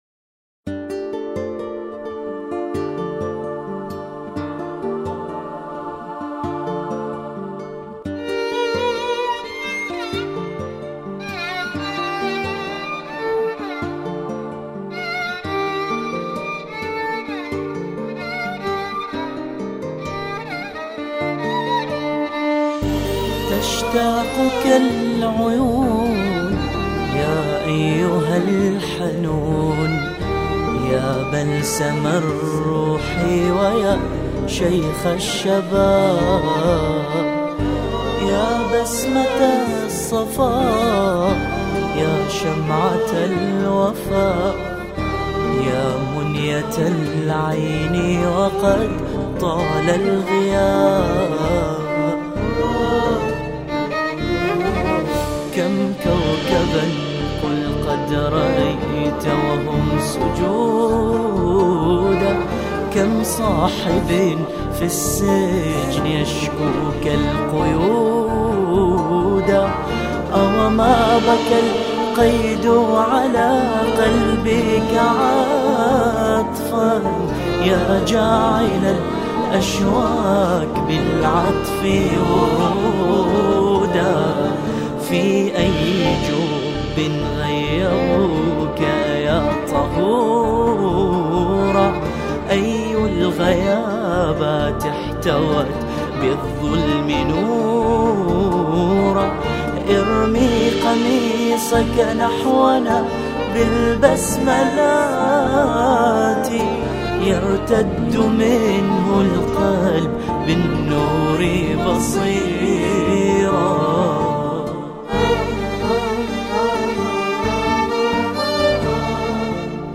أناشيد بحرينية